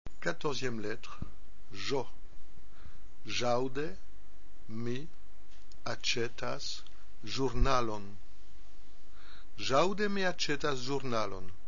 14 - Ĵ J
-2) la phrase prononcée lentement en séparant bien les mots,
-3) la phrase prononcée normalement.